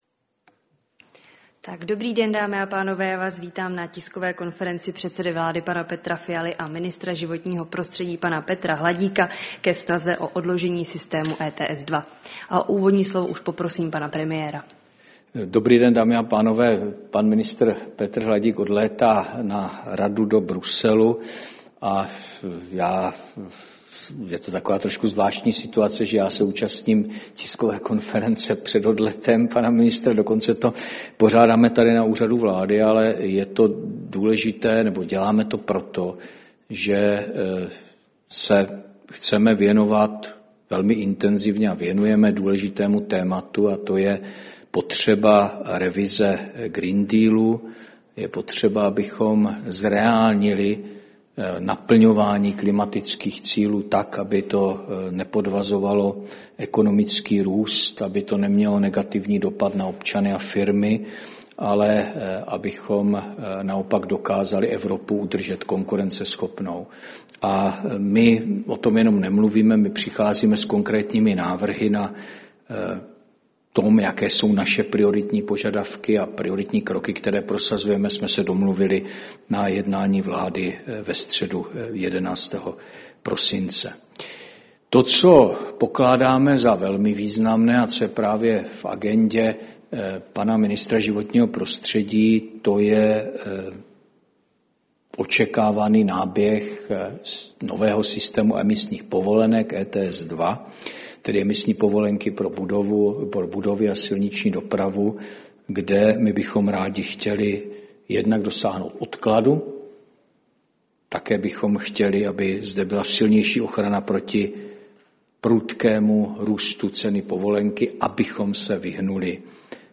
Tisková konference premiéra Fialy a ministra životního prostředí Hladíka k snaze o odložení systému ETS 2